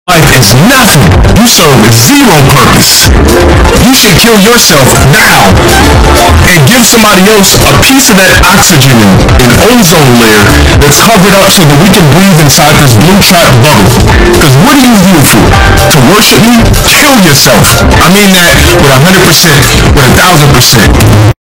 Earrape